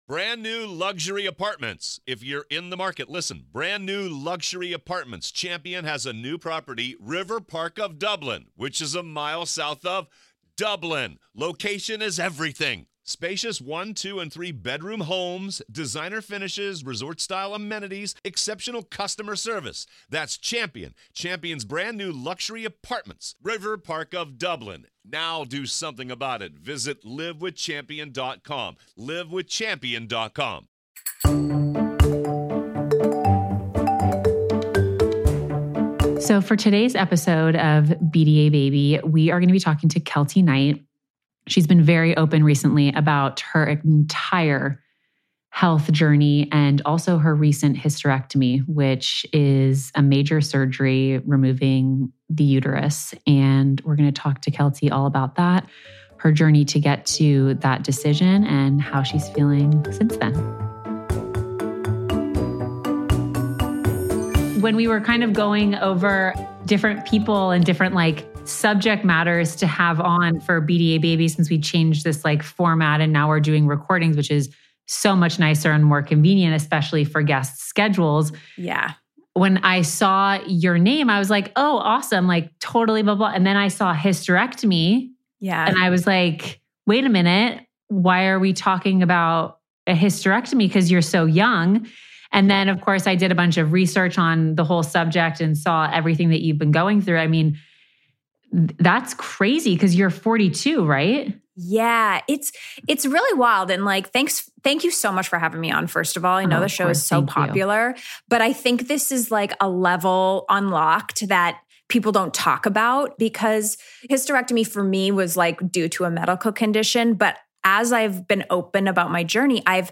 This week, Keltie Knight joins us to discuss her health journey over the past ten years, which led to her having a hysterectomy. Like many women, Keltie struggled for years with painful periods and was often dismissed by doctors who attributed her pain to womanhood.